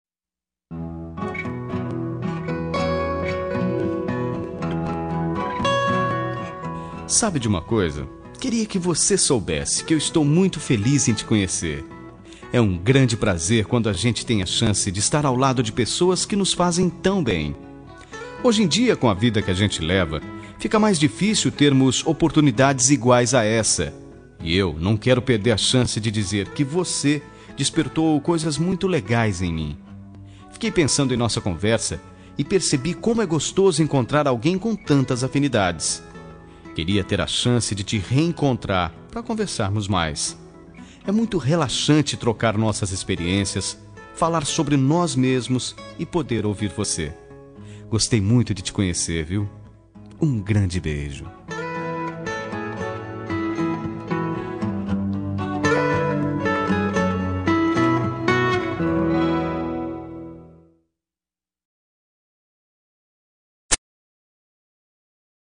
Telemensagem Momentos Especiais – Voz Masculina – Cód: 201885 -Adorei te Conhecer